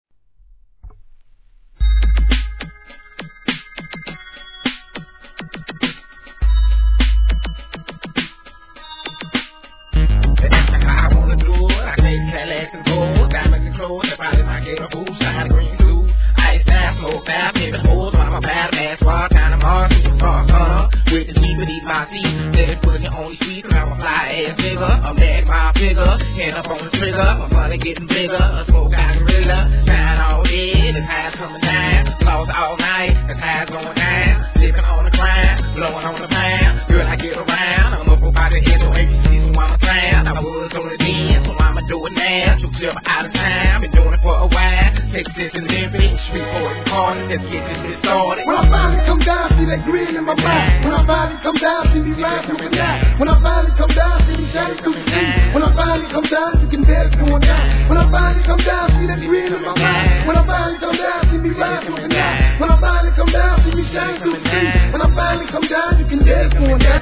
G-RAP/WEST COAST/SOUTH
不穏なシンセとピアノのがダークなBEATで個性的なフロウのMICリレーを披露!!